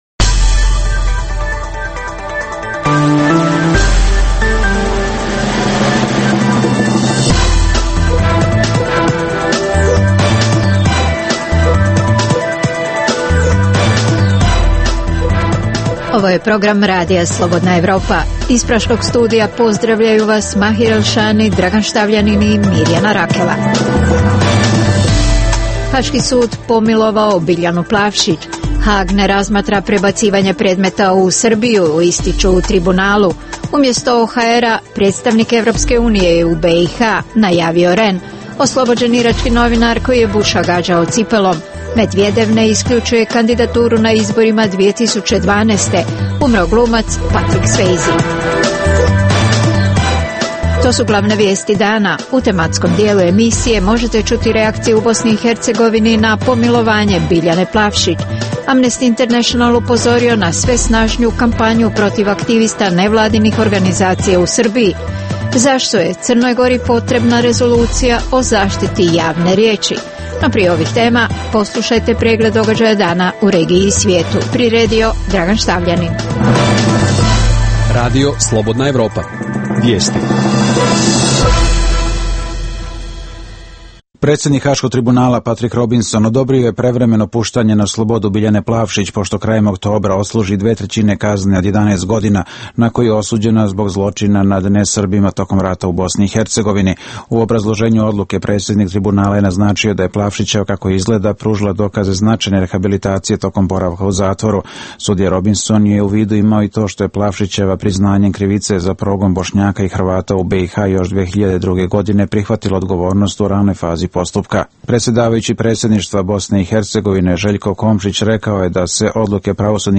U regionalnom programu možete čuti reakcije u BiH na pomilovanje Biljane Plavšić; Amnesty International upozorio na sve snažniju kampanju protiv aktivista nevladinih organizaciju u Srbiji; zašto je Crnoj Gori potrebna rezolucija o zaštiti javne riječi. Godinu dana nakon bankrota najveće američke investicijske banke "Lehman Brothers" gost našeg programa je američki profesor Allan H. Meltzer.